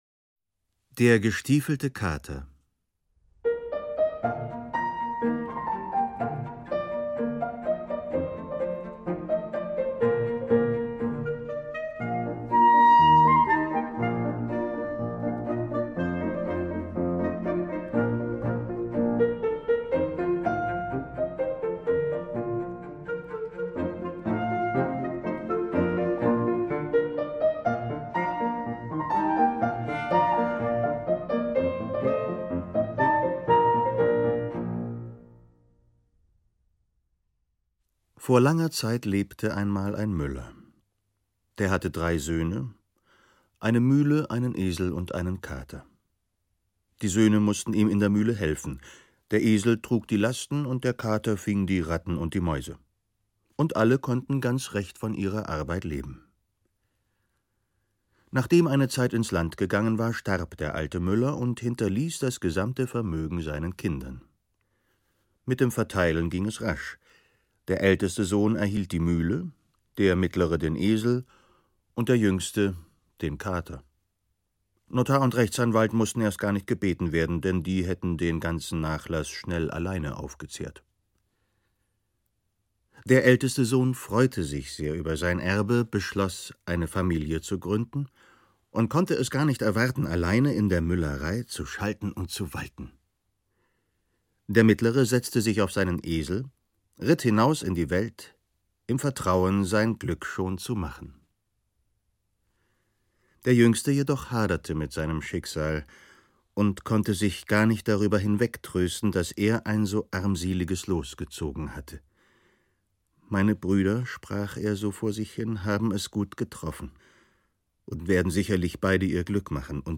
Klarinette
Violoncello
Klavier
Erzähler: Ulrich Noethen